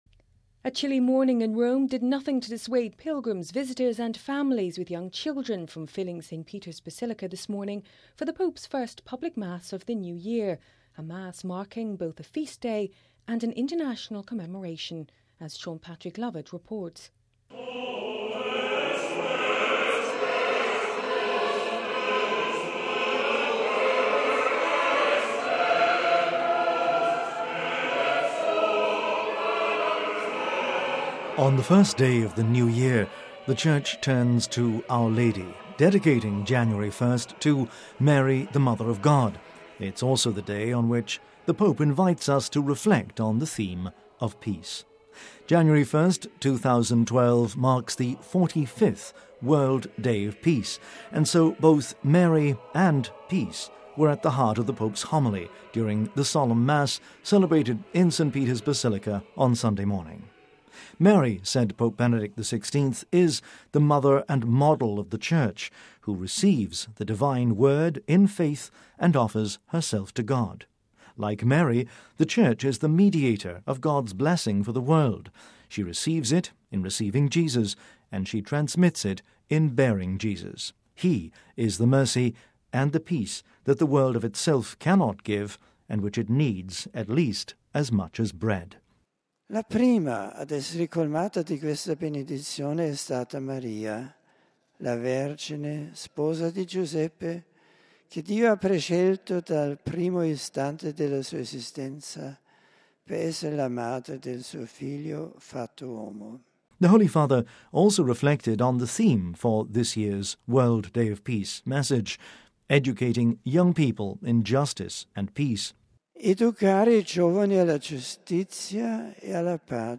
January 1st 2012 marks the 45th World Day of Peace and so both Mary and Peace were at the heart of the Pope’s homily during the solemn Mass celebrated in St Peter’s Basilica on Sunday morning.